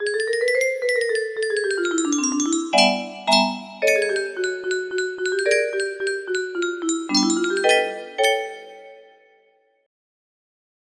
2 draft with opinion over the numbers music box melody